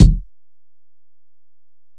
Kick (2).wav